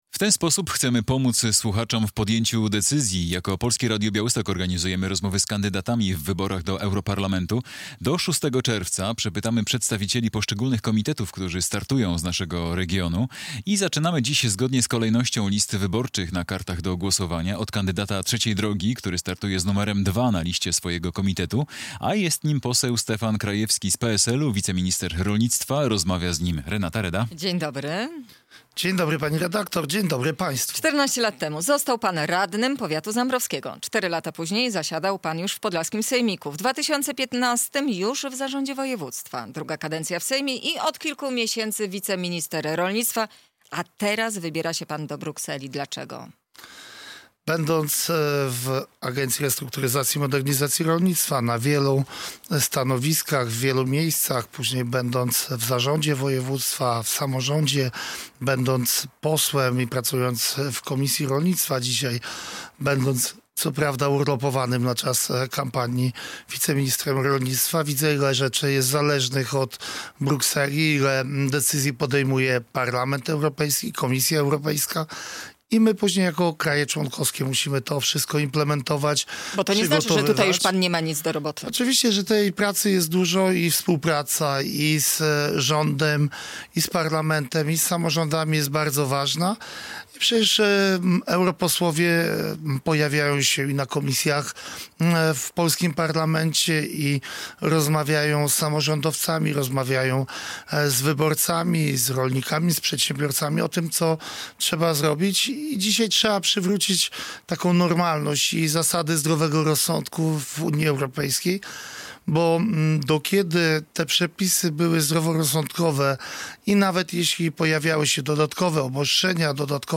Radio Białystok | Gość | Stefan Krajewski - poseł, wiceminister rolnictwa, kandydat Trzeciej Drogi do Parlamentu Europejskiego